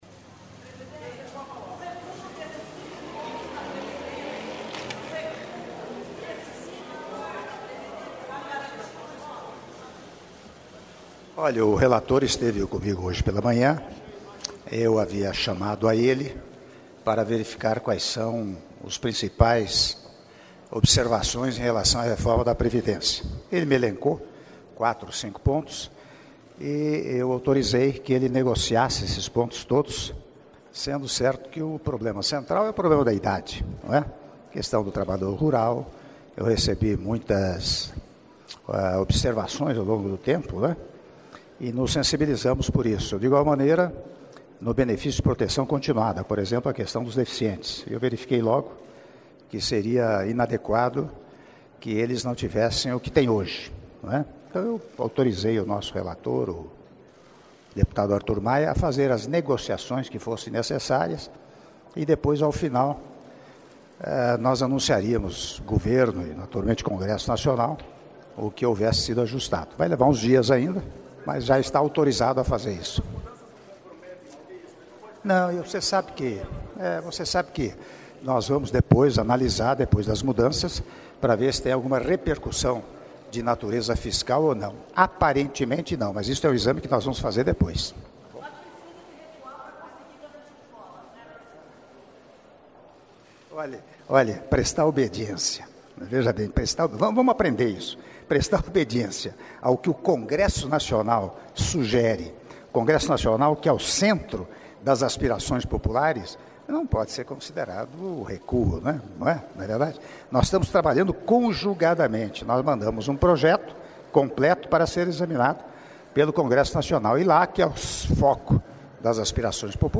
Áudio da entrevista coletiva concedida pelo Presidente da República, Michel Temer, após almoço em homenagem ao Rei e Rainha da Suécia - Brasília/DF (02min28s)